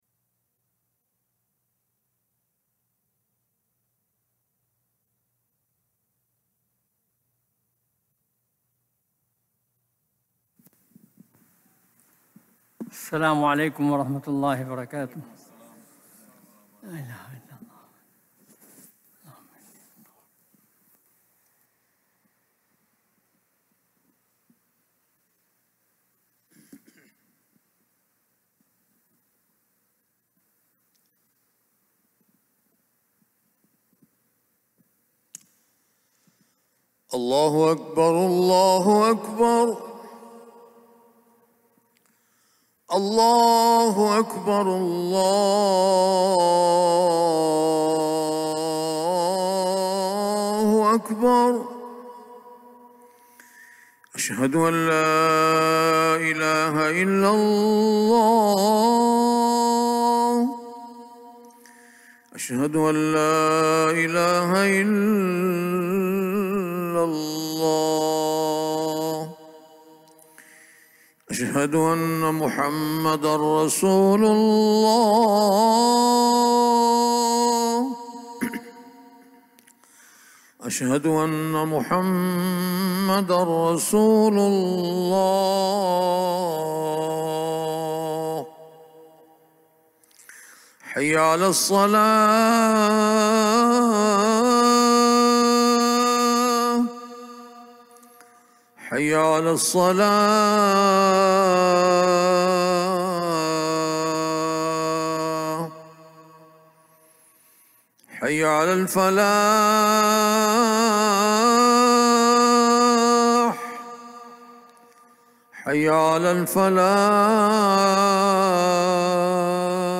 Friday Khutbah - "Purpose of Learning"